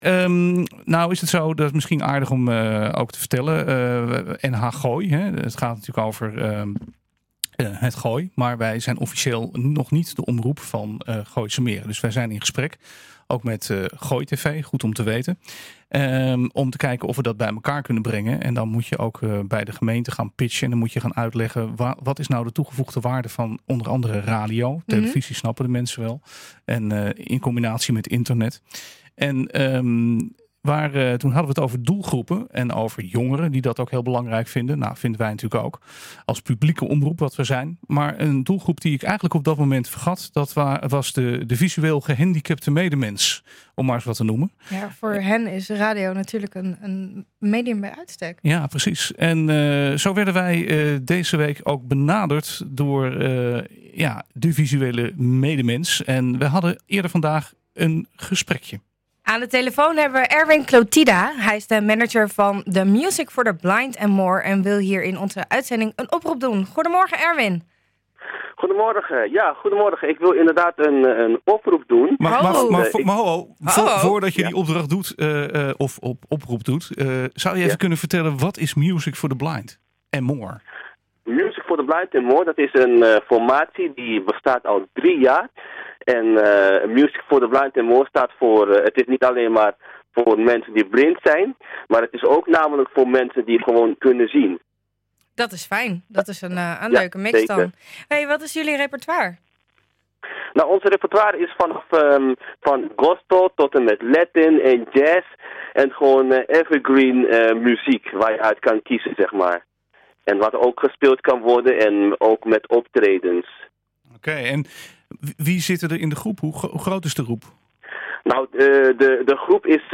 We spraken met hem in de uitzending en hij deedt een oproep.